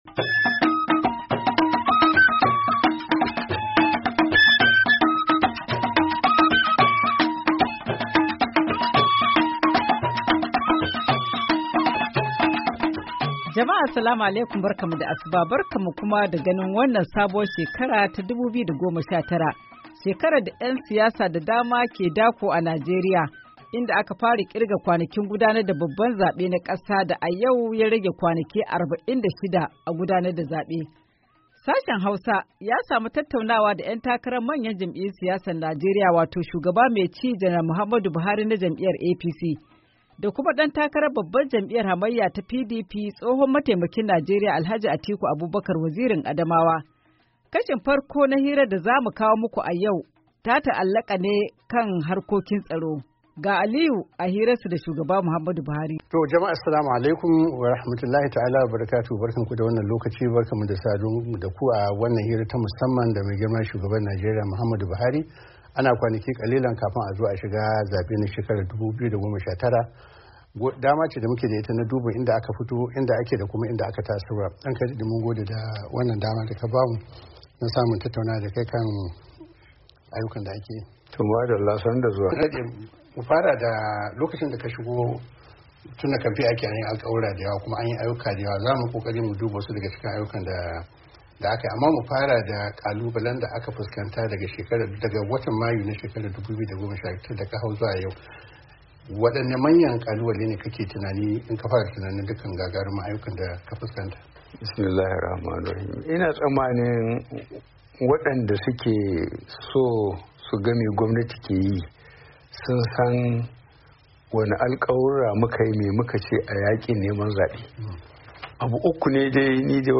Hira Da Shugaba Buhari da Alh Atiku PT1